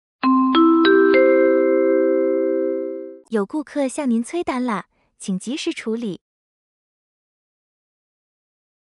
新提示音+语音 1-5.mp3